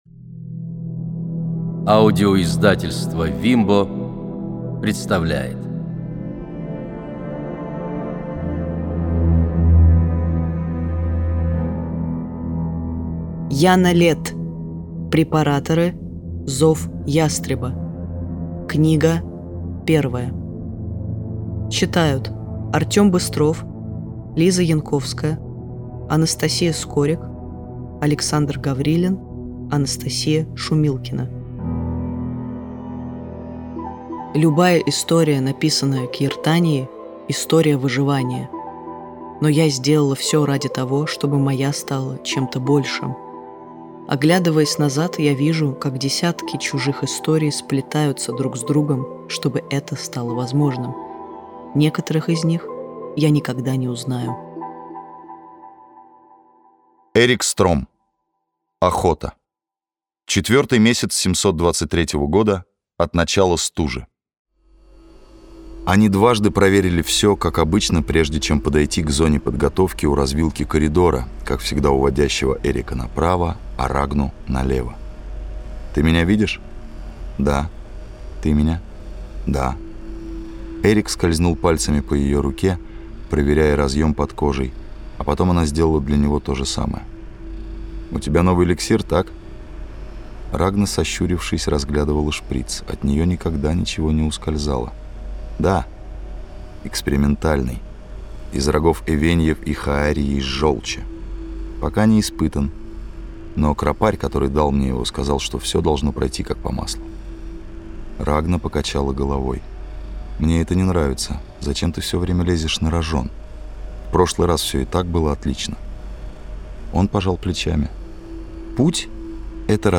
Препараторы. Зов ястреба (слушать аудиокнигу бесплатно) - автор Яна Летт